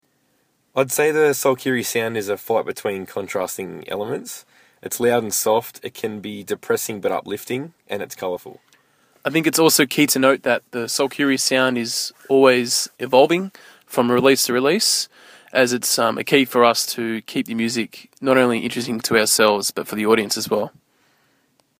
SOLKYRI INTERVIEW – May 2013